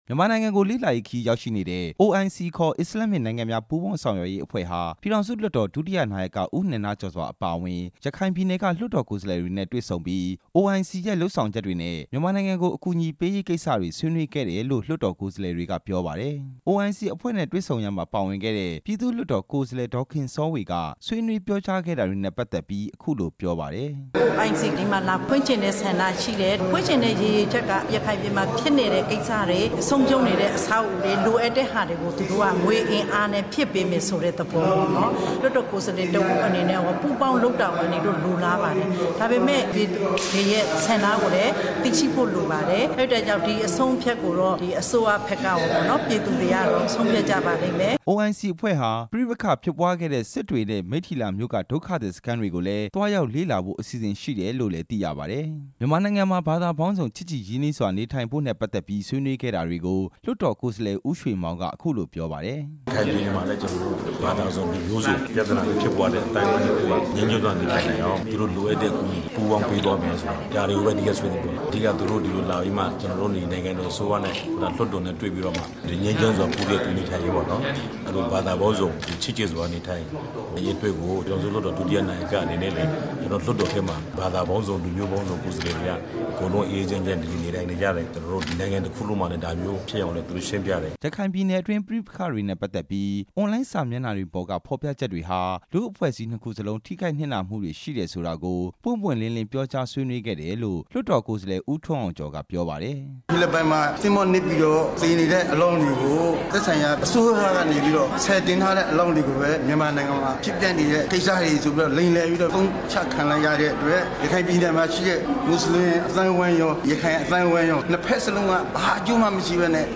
အိုအိုင်စီကိုယ်စားလှယ်အဖွဲ့ဟာ လွှတ်တော်ဝင်း အတွင်းက I ဆောင်မှာ ပြည်ထောင်စုလွှတ်တော် ဒုတိယနာယက ဦးနန္ဒကျော်စွာ အပါအဝင် ရခိုင်ပြည်နယ်က လွှတ်တော်ကိုယ်စားလှယ်တွေ နဲ့ တွေ့ဆုံစဉ် ဆွေးနွေးခဲ့တာတွေနဲ့ ပတ်သက်လို့ ပြည်သူ့လွှတ်တော် ကိုယ်စားလှယ် ဒေါ်ခင်စောဝေက အခုလိုပြောပါတယ်။